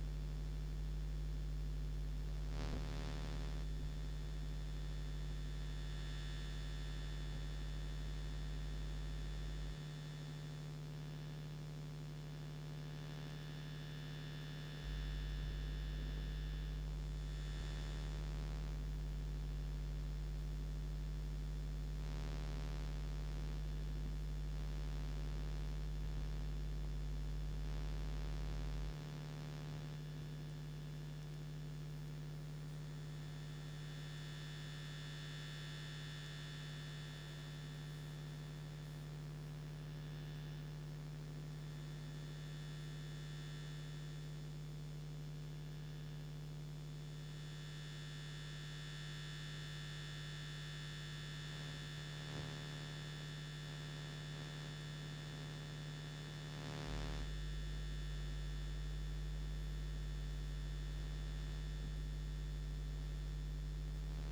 ELF/VLF excerpt